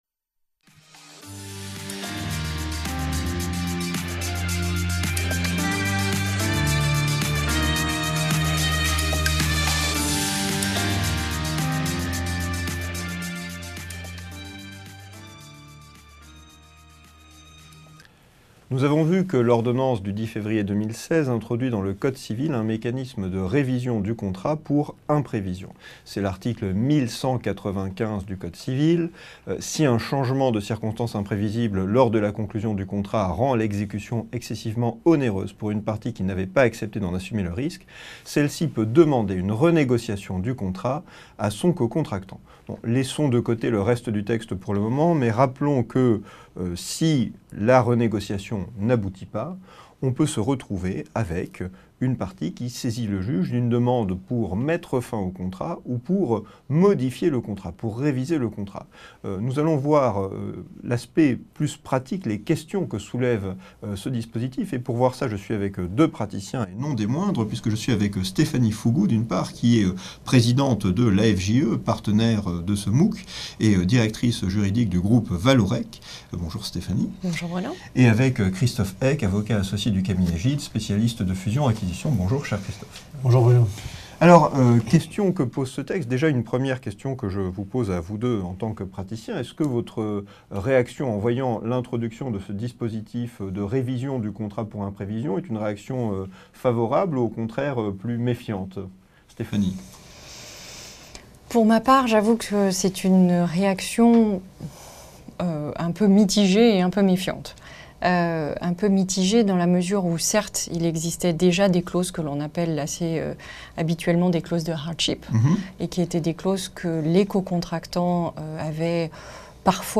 Cette vidéo fait partie du dispositif pédagogique du MOOC Sorbonne Droit des contrats, qui a connu sa première session entre mars et avril 2016 sur la plate-forme France Université Numérique (FUN).